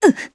Isolet-Vox_Damage_jp_01.wav